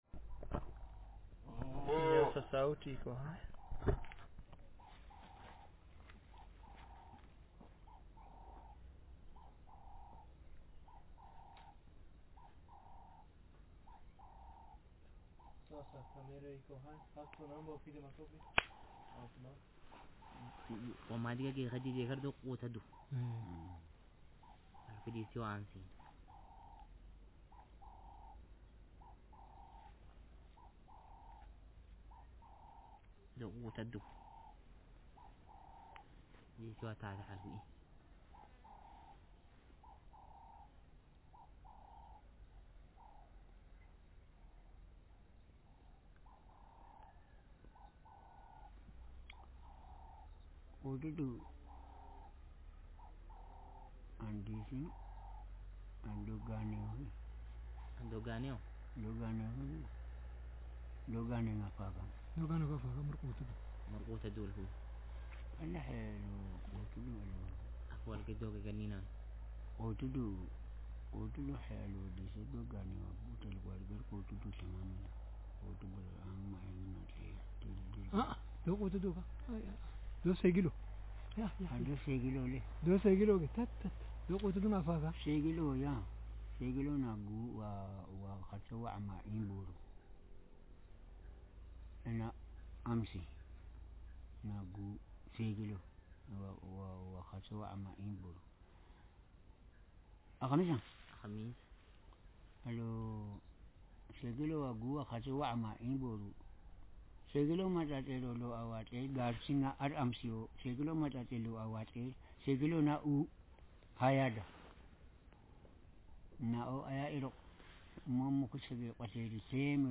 Speaker sex m Text genre conversation